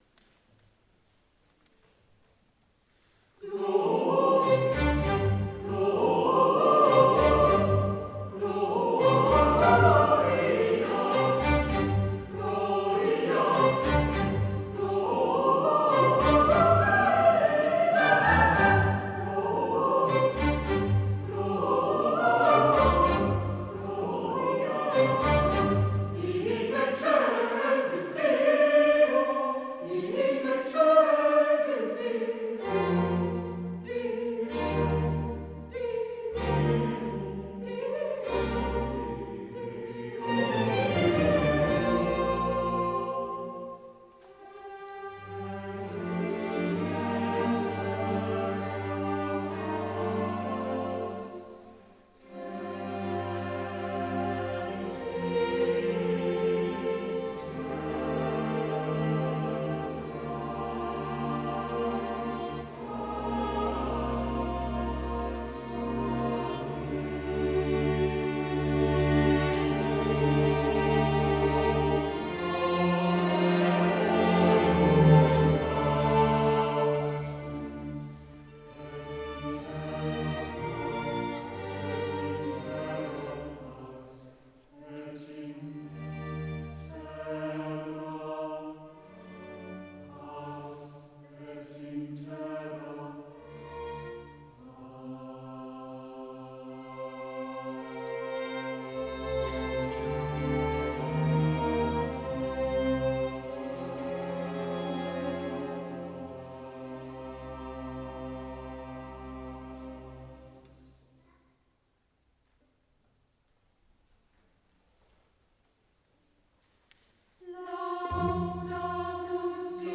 für Chor und Streichorchester